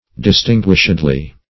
Search Result for " distinguishedly" : The Collaborative International Dictionary of English v.0.48: Distinguishedly \Dis*tin"guish*ed*ly\, adv.